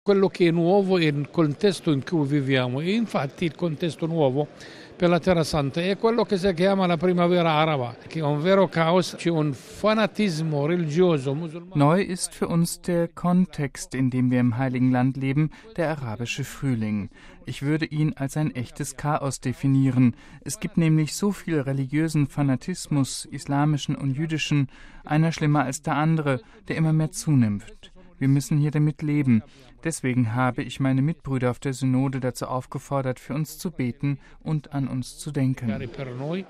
Die Christen seien daher auf die Solidarität ihrer Glaubensgeschwister weltweit angewiesen, so der Patriarch Fouad Twal in Anspielung auf jüngste Vandalismusakte gegen christliche Einrichtungen im Hl. Land. Twal bedauerte im Gespräch mit Radio Vatikan die zunehmende Abwanderung von einheimischen Christen und forderte zu Pilgerfahrten ins Heilige Land auf – gerade mit Blick auf die Neuevangelisierung.